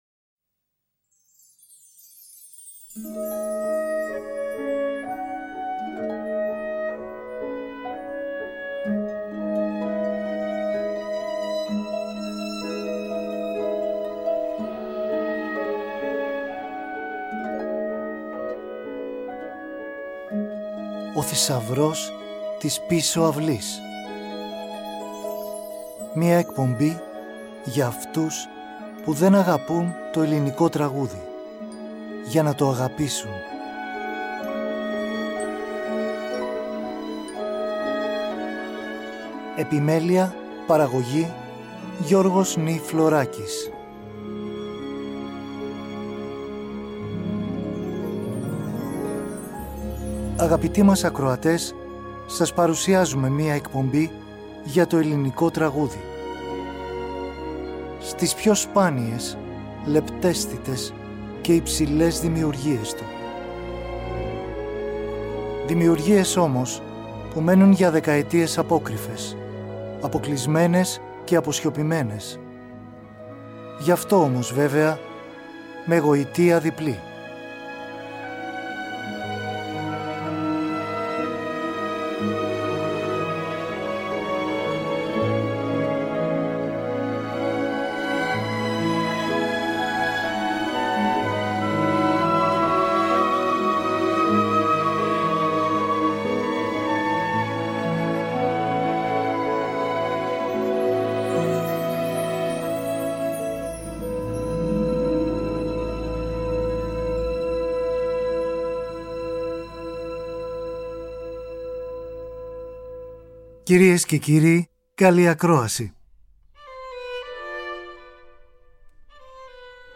μουσική και τραγούδια